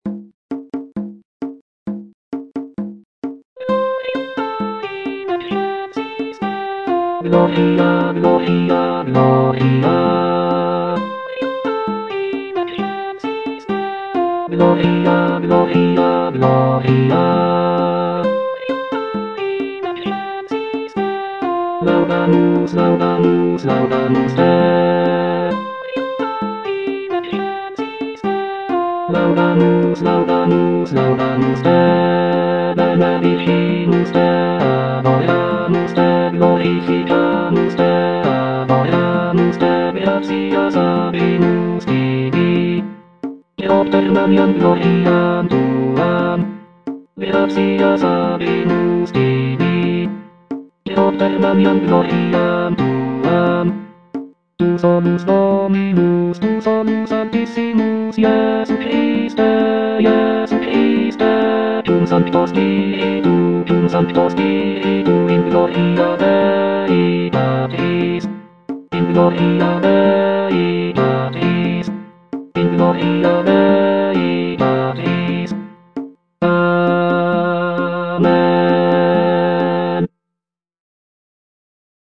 Bass (Emphasised voice and other voices) Ads stop